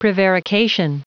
Prononciation du mot prevarication en anglais (fichier audio)